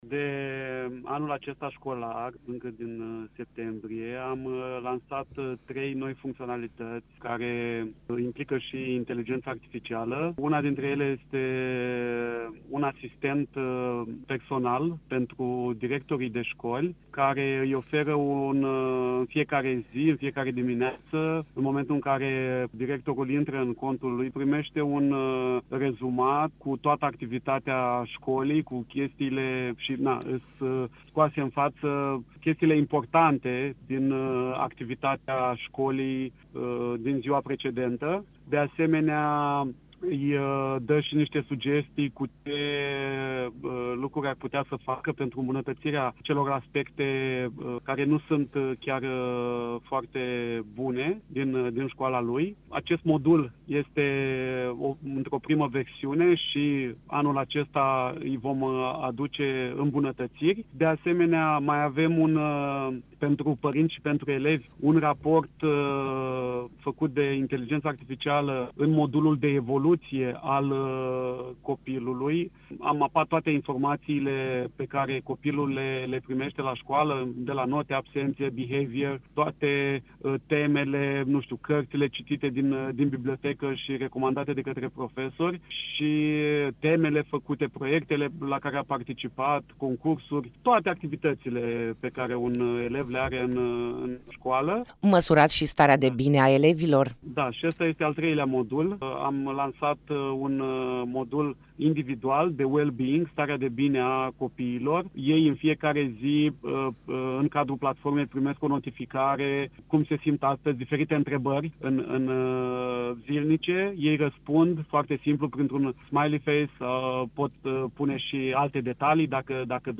Interviu-integrarea-IA-in-catalogul-electronic.mp3